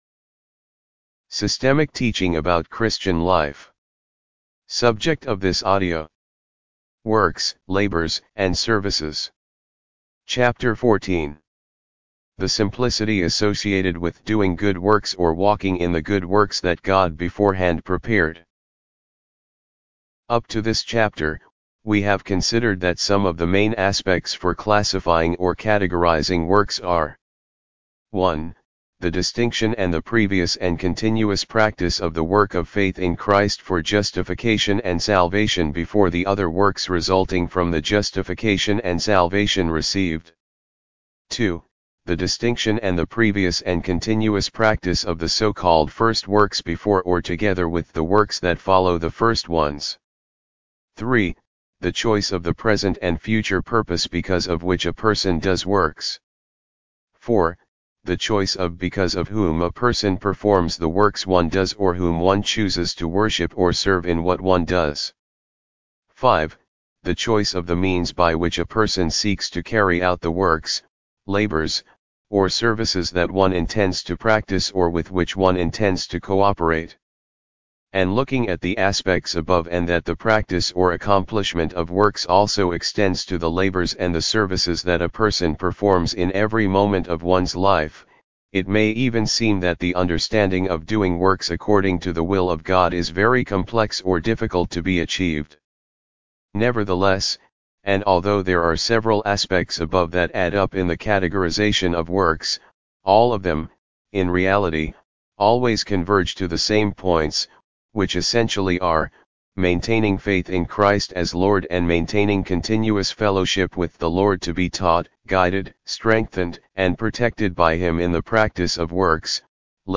Audibooks - Systemic Teaching about Christian Life Audio Book divided into chapters.